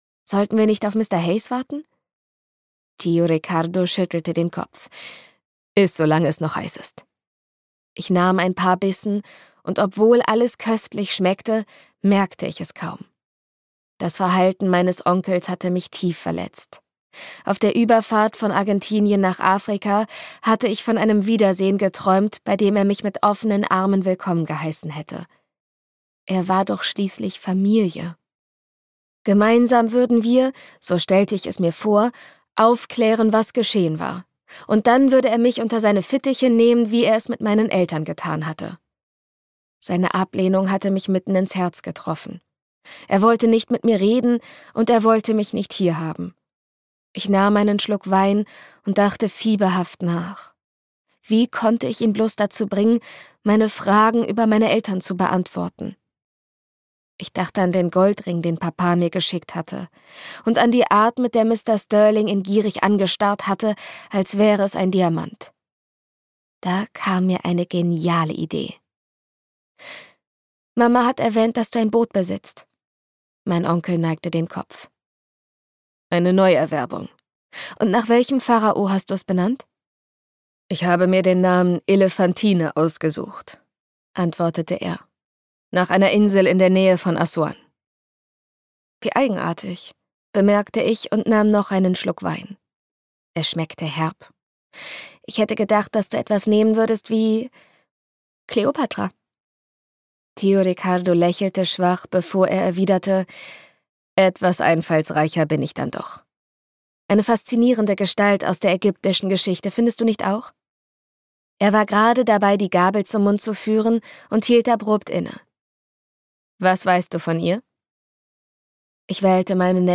Auch dies mit einer sehr emotionalen, rauchigen Stimme.